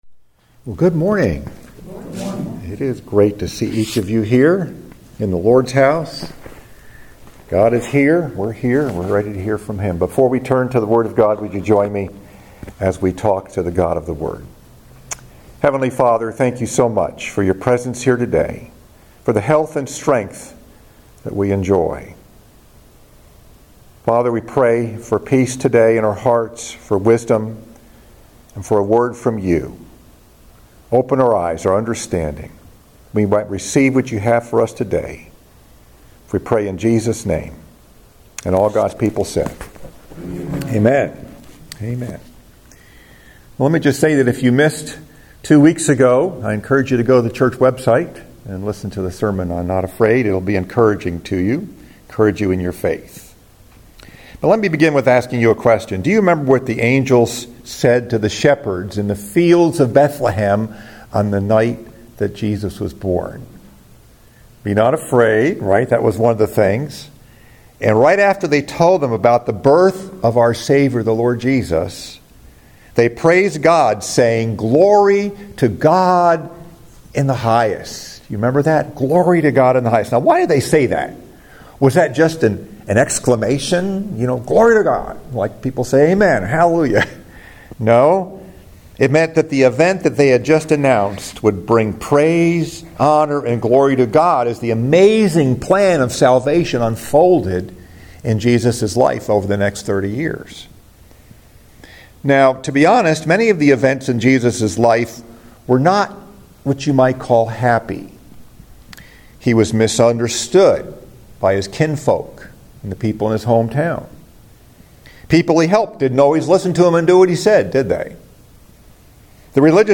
Message: “Glory to God!”